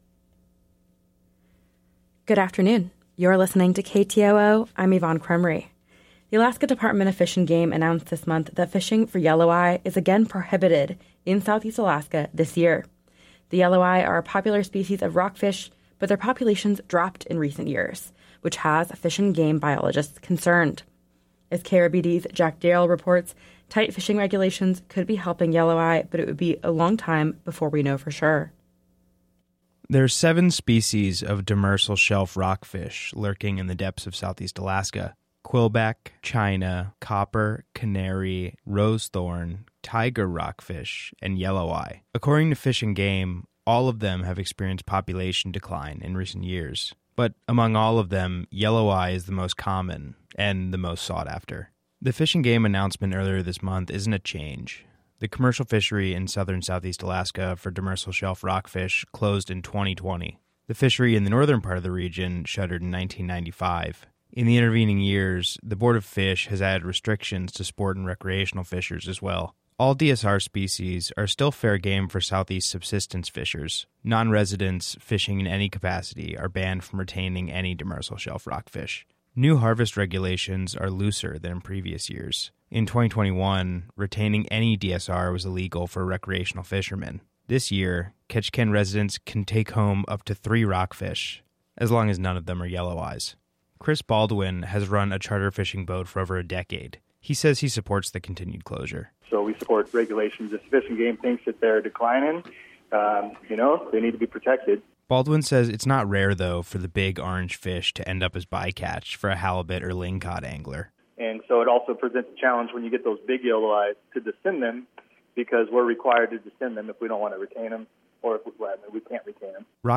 Newscast – Wednesday, Jan. 31, 2024